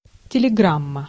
Ääntäminen
IPA : /ˈtɛləˌɡɹæm/